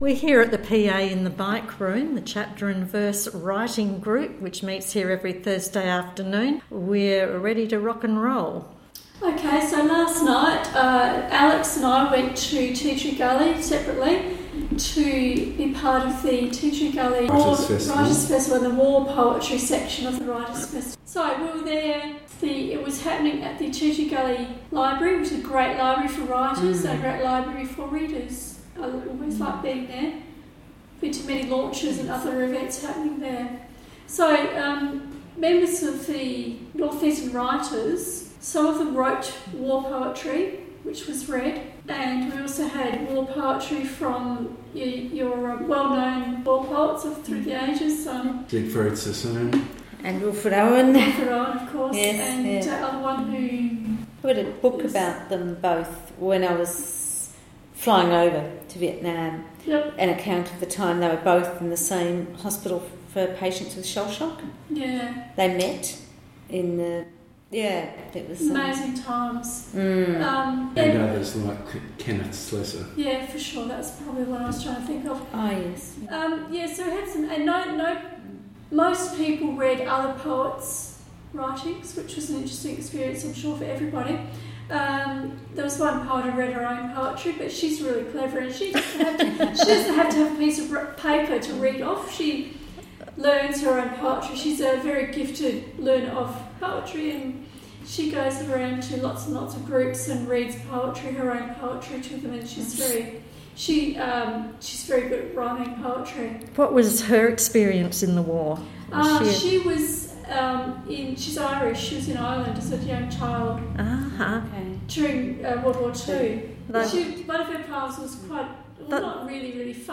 The Writers Group host their first discussion-based podcast, as they discuss war poetry concerning both World Wars, as they commemorate Remembrance Day.